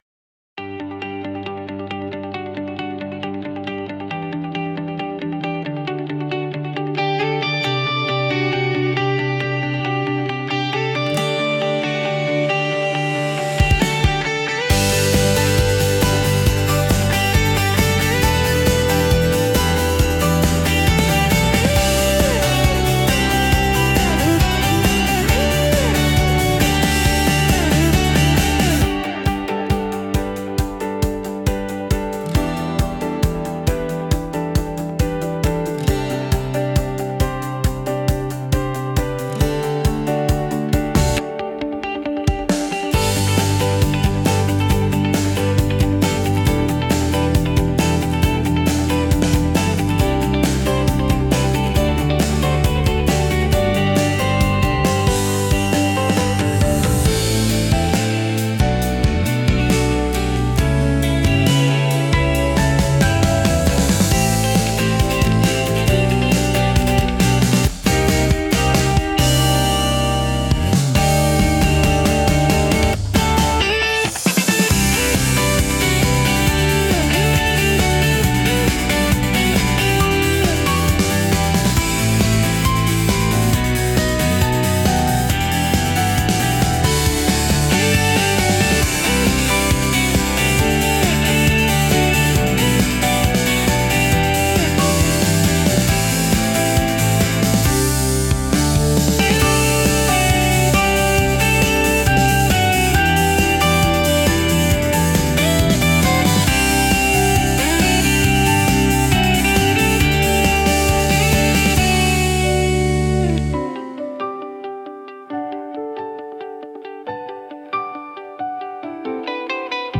Inspirational Business Score